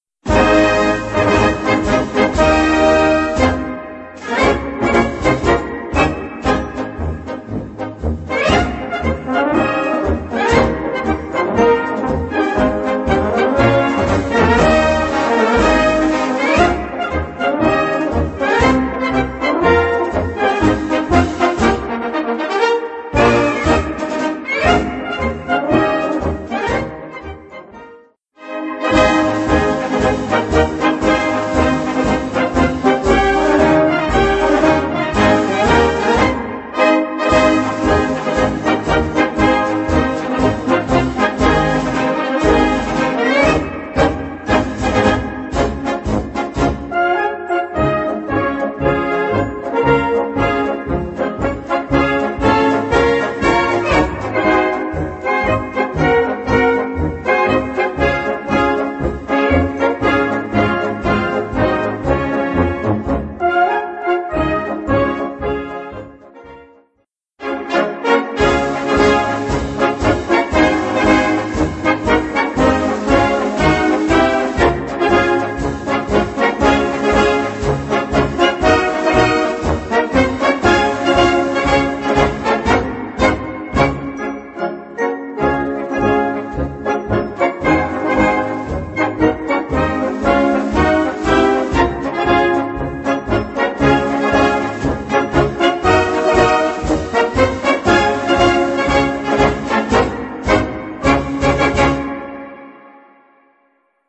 Gattung: Marsch, op. 17
Besetzung: Blasorchester